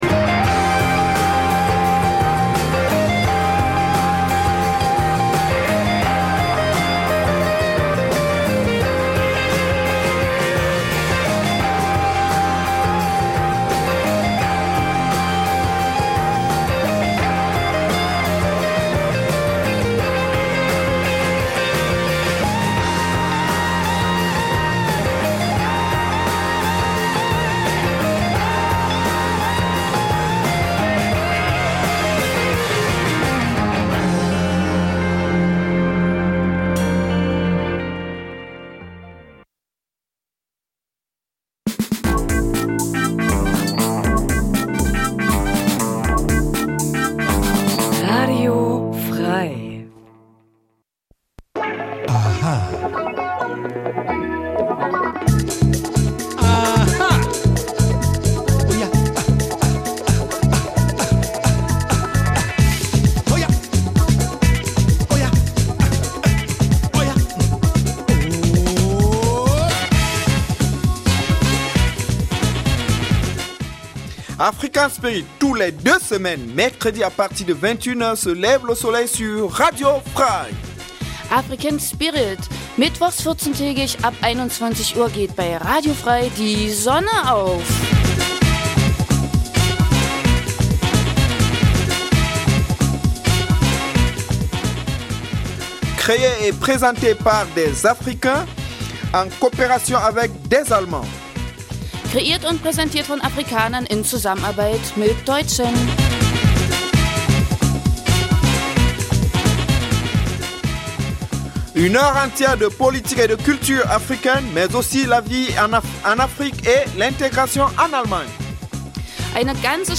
Afrikanisches zweisprachiges Magazin Dein Browser kann kein HTML5-Audio.
Die Gespräche werden mit afrikanischer Musik begleitet.